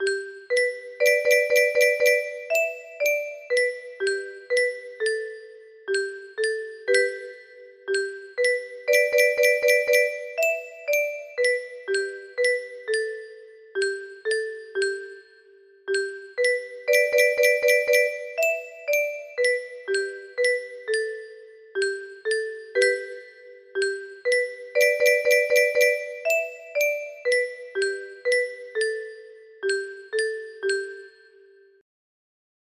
Example 2 music box melody